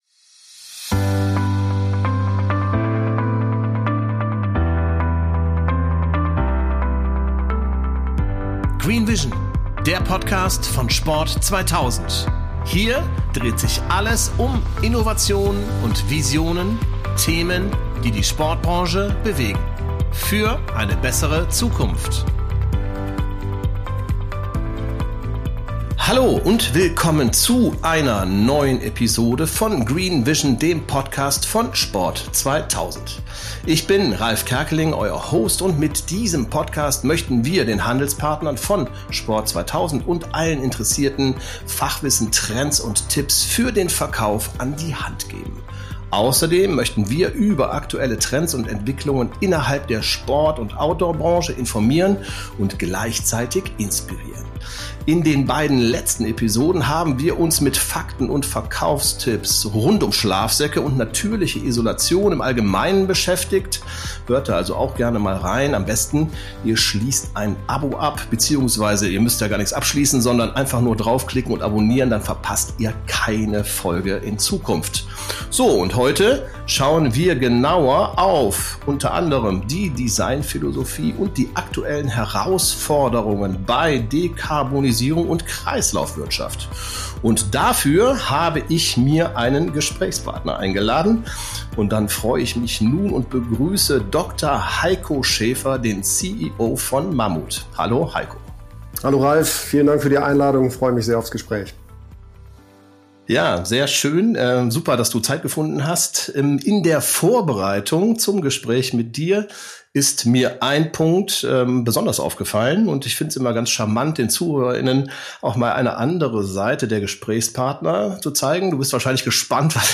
Zu finden auf: Spotify, Apple Podcasts & Co. Das Interview wurde am 21.03.2025 aufgenommen.